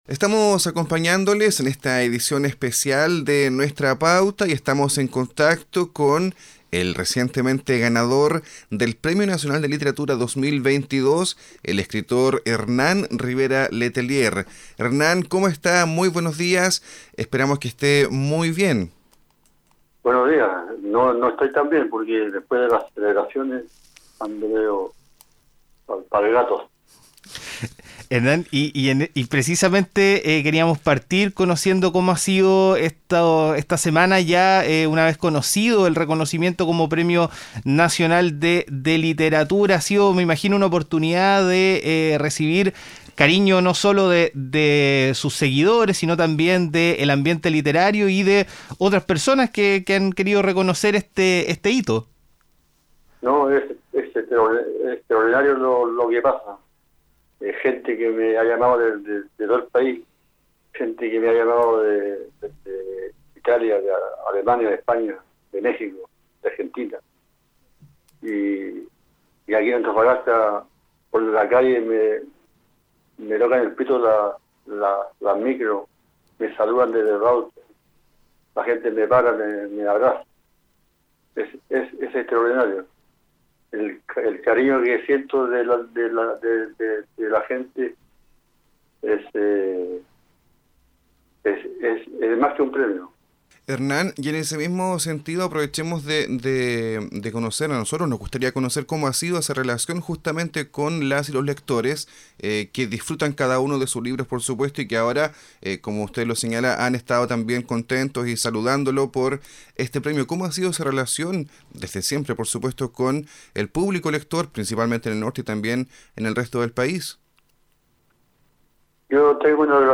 Entrevista-Hernan-Rivera-Letelier.mp3